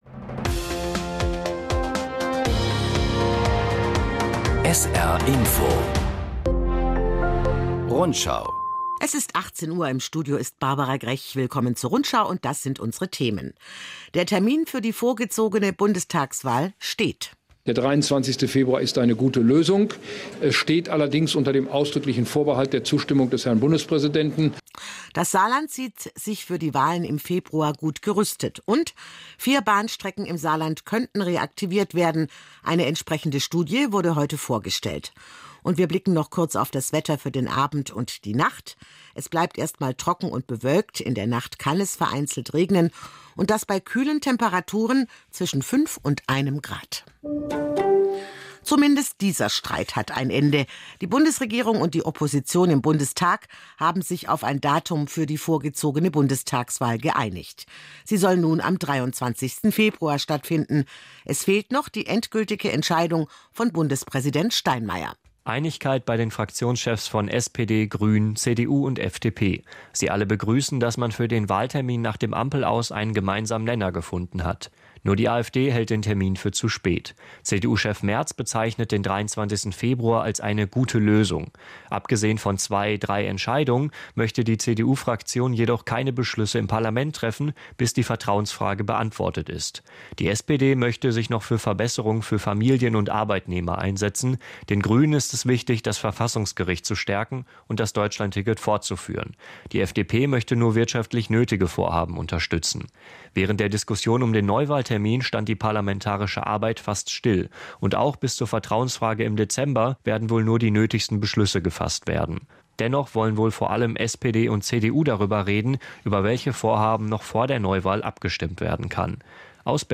… continue reading 5 قسمت # Nachrichten